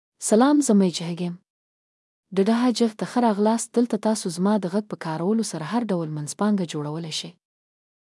Latifa — Female Pashto (Afghanistan) AI Voice | TTS, Voice Cloning & Video | Verbatik AI
LatifaFemale Pashto AI voice
Voice sample
Listen to Latifa's female Pashto voice.
Female
Latifa delivers clear pronunciation with authentic Afghanistan Pashto intonation, making your content sound professionally produced.